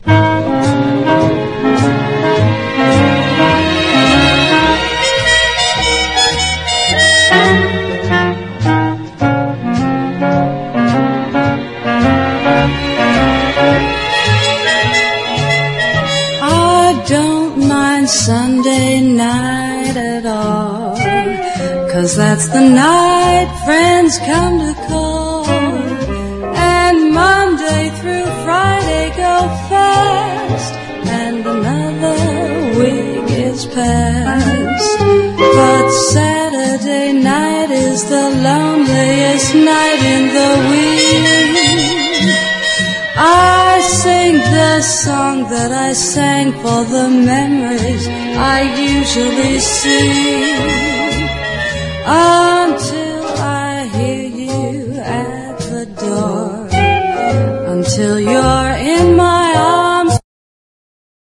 REGGAE / SKA/ROCKSTEADY